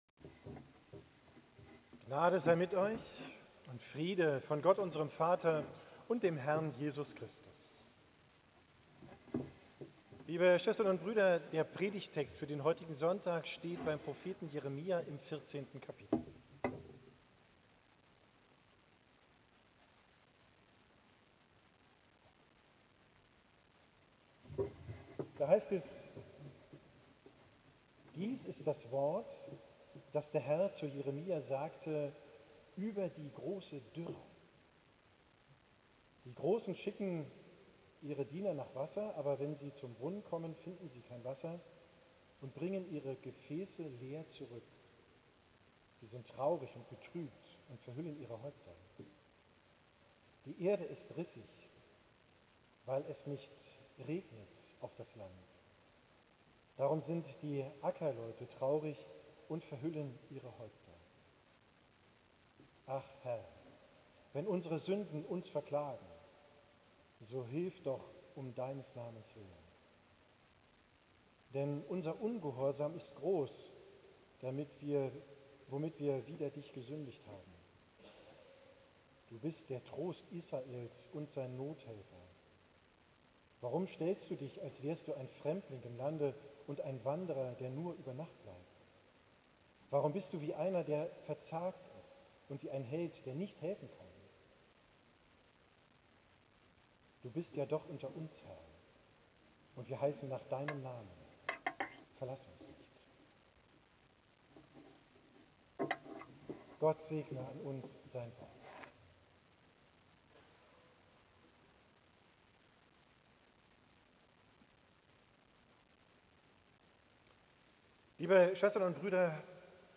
Predigt vom 2.